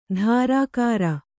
♪ dhārākāra